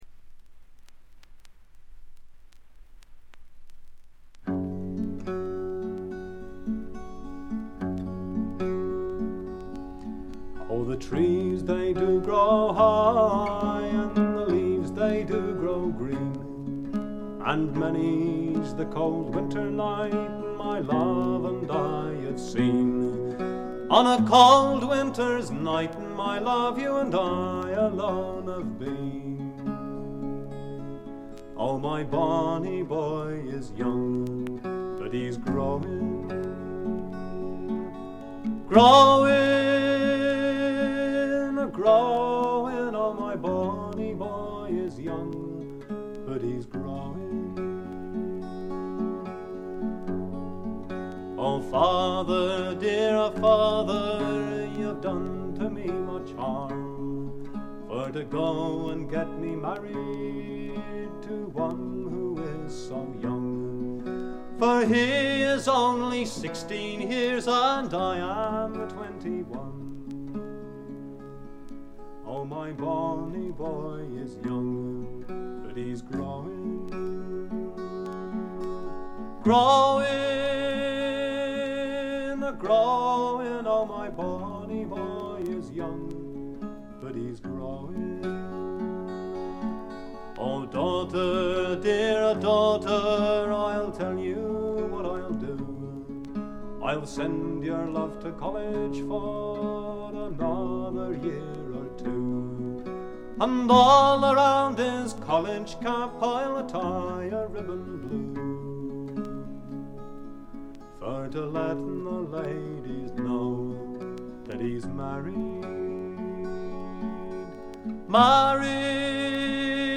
ところどころで軽微なチリプチ。目立つノイズはありません。
英国フォーク必聴盤。
Stereo盤。
試聴曲は現品からの取り込み音源です。